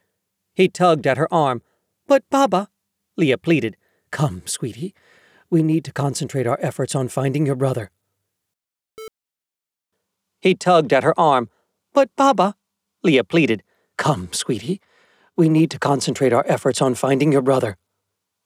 The acoustics in my booth have changed and I am noticing some frequencies ‘ringing’ in there.